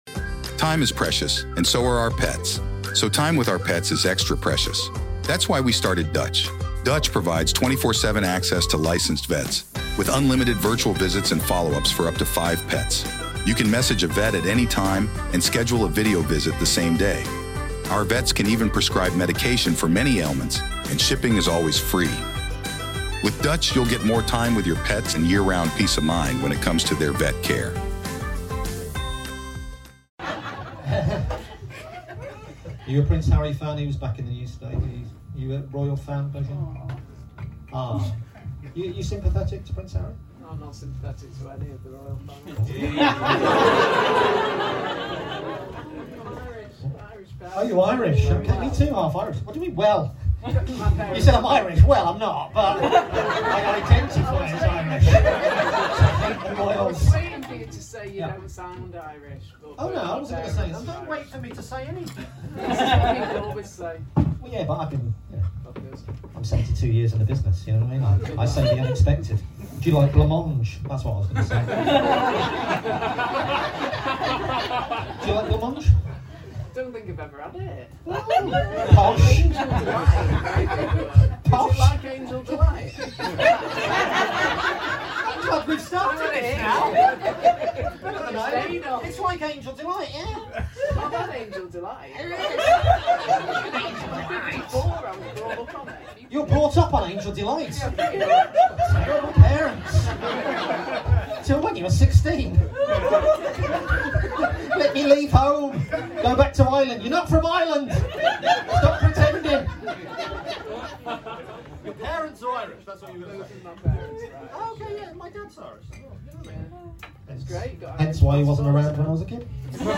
Season 4 is a limited season of shorter bits mainly without piano
Recorded Live at The Rat Pack @ Camden Comedy Club Dec 2023.